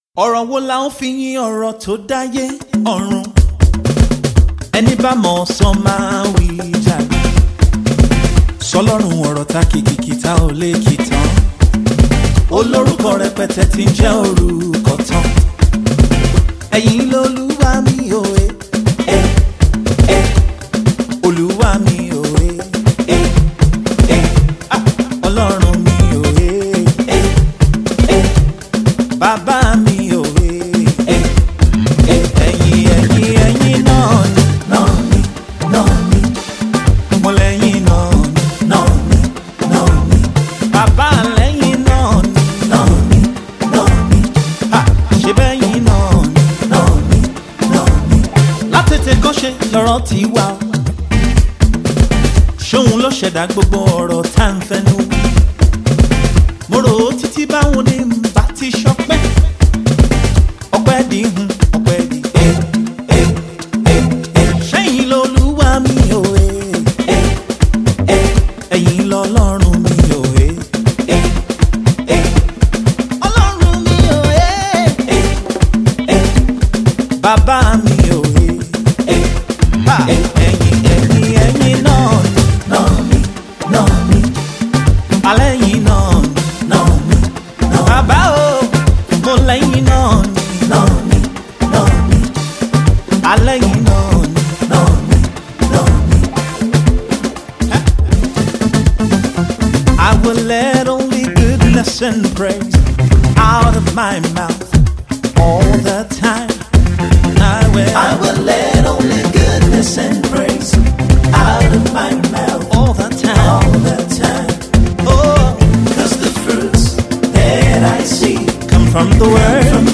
This is a celebration song
One you can call ‘Alujo’ or ‘owambe’ music.